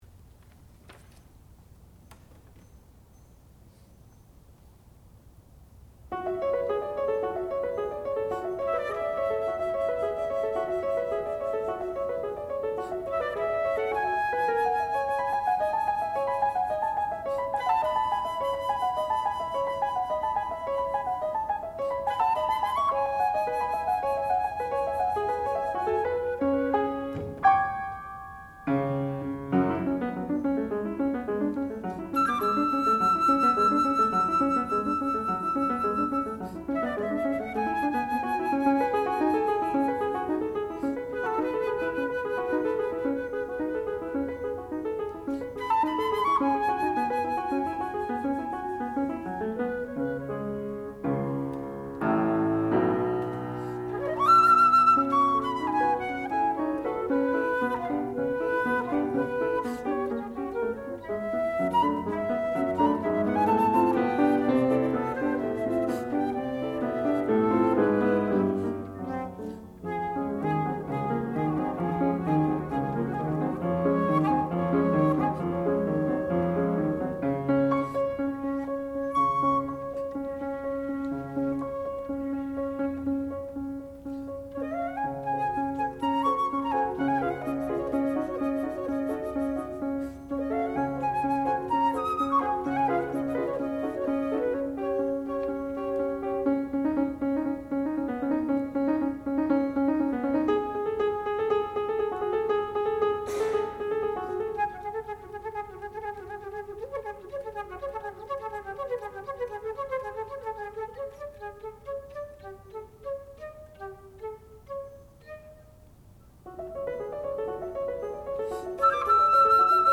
classical music
piano
flute
Advanced Recital